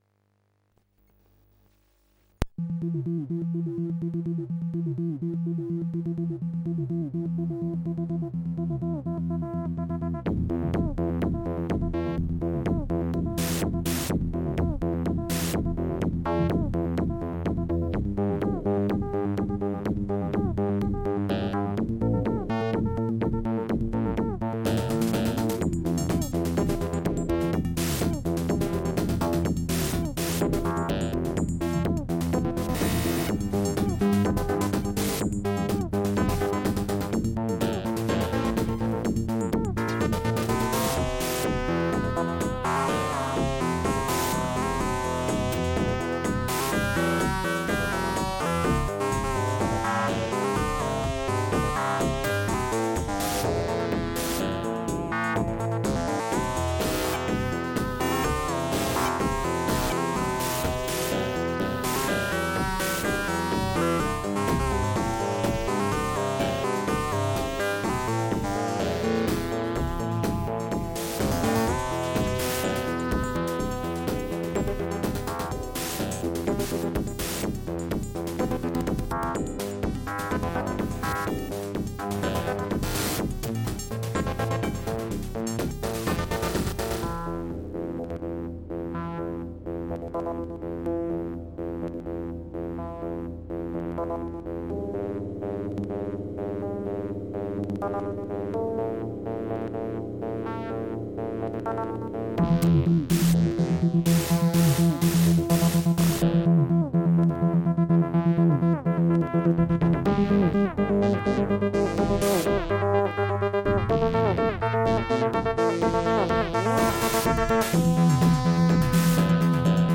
FM, cos jeszcze nawala